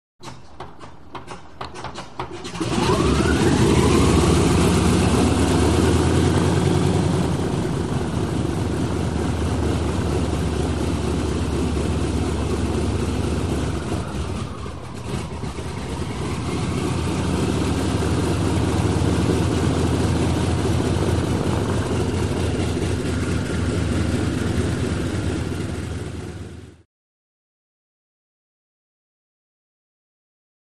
Prop Airplane Start Up & Idle, Twin Engine C-123.